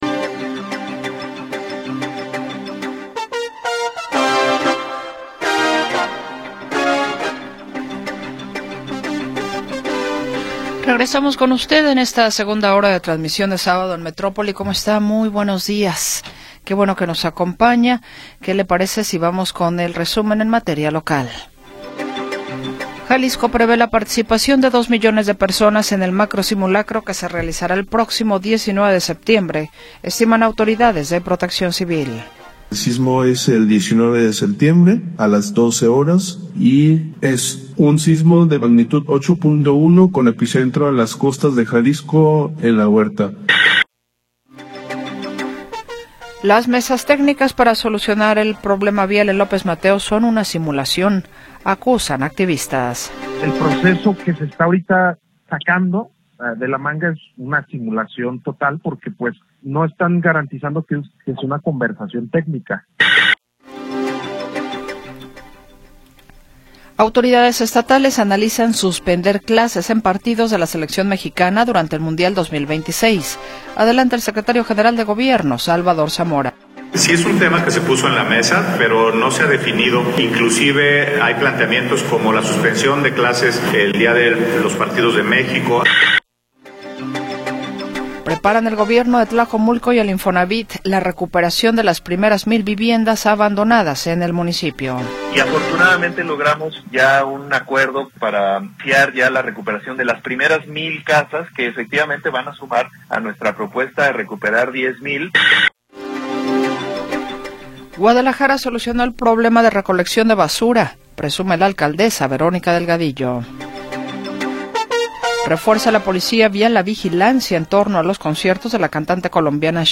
6 de Septiembre de 2025 audio Noticias y entrevistas sobre sucesos del momento